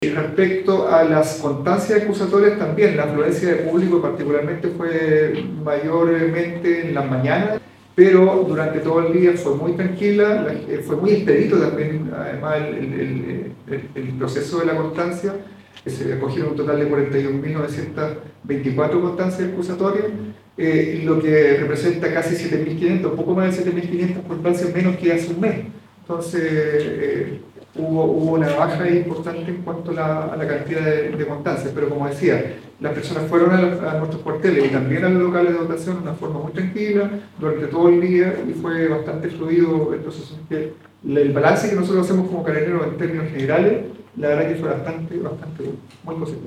Finalmente, el general de zona de Carabineros, Héctor Valdés, señaló que se recibieron más de 41 mil constancias de excusas por no votar en la región.